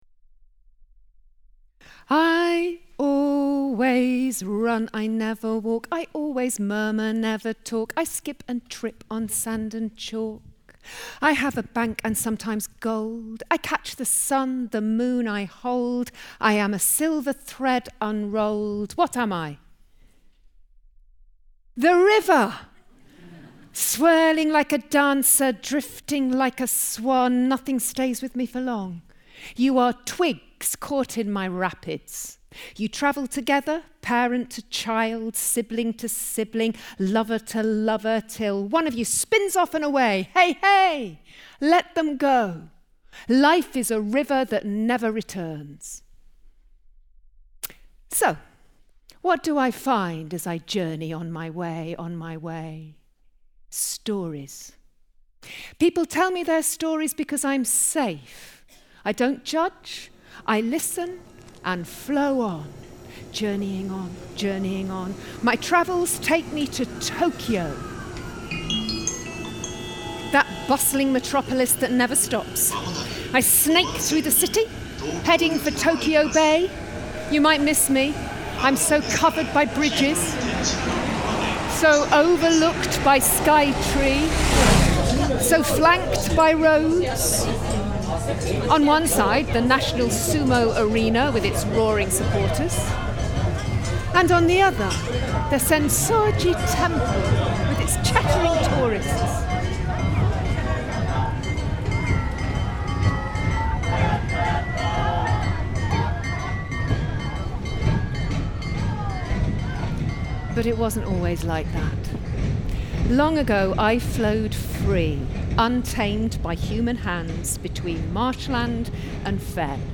For our first headline event of Noh Reimagined 2024, Mu Arts and Kings Place were proud to present a rare performance of ‘Sumidagawa’, one of Noh Theatre’s greatest masterpieces.